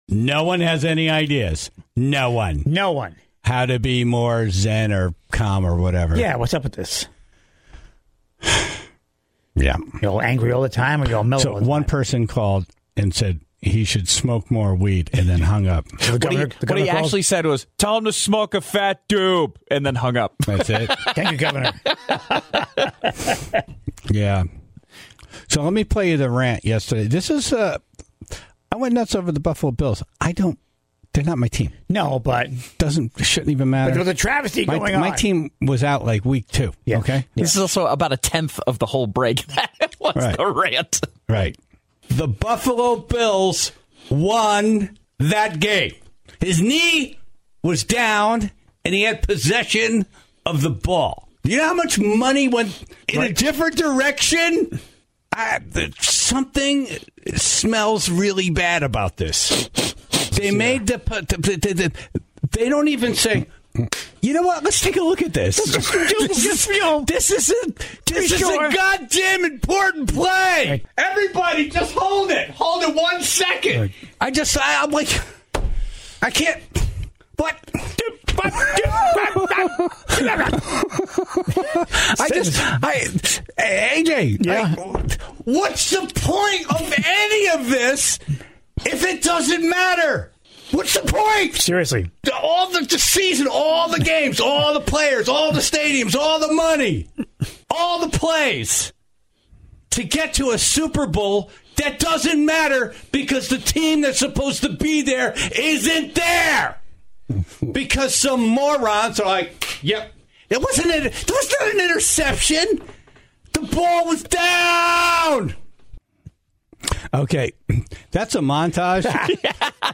The Tribe called in their suggestions, including the cleansing sounds of bowl therapy.